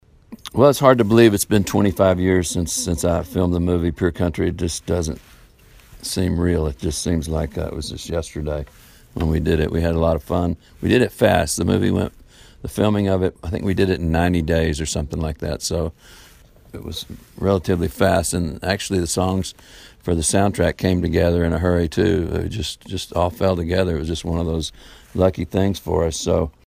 Audio / George Strait talks about the 25th anniversary of Pure Country.